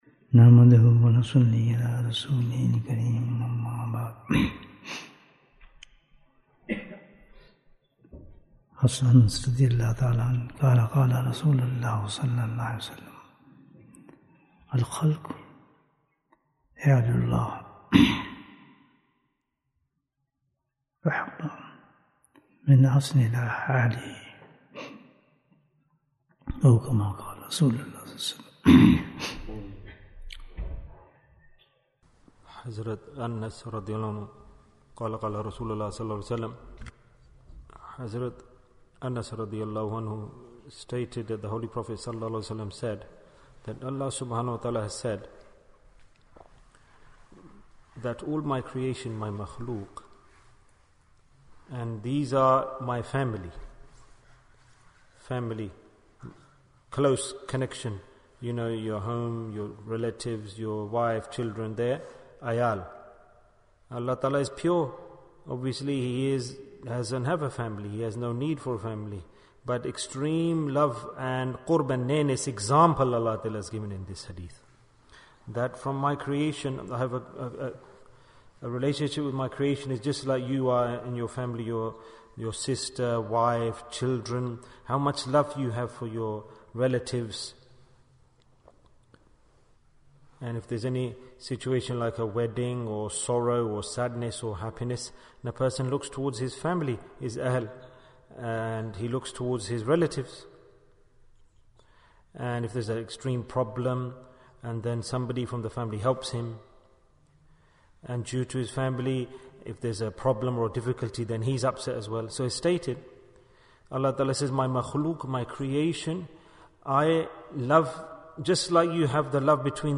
Why Does the First Asharah Start With Rahmah? Bayan, 45 minutes1st April, 2023